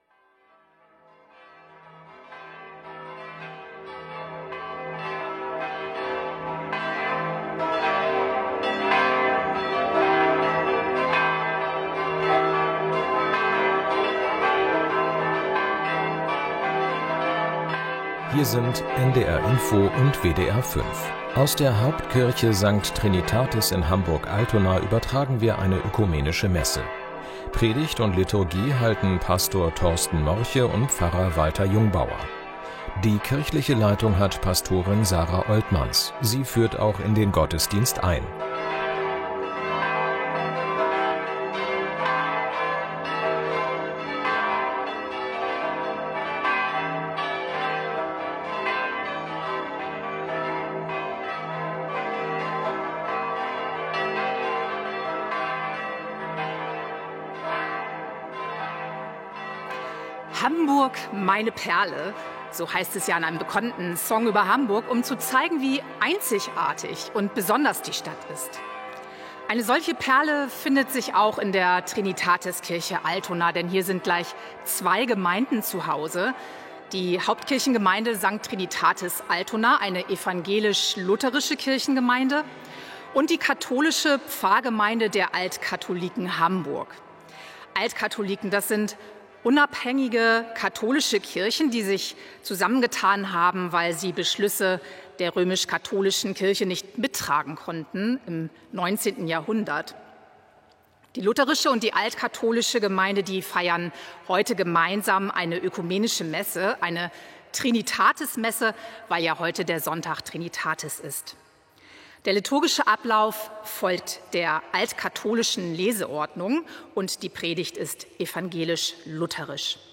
Radio-Gottesdienst zum Sonntag Trinitatis 2024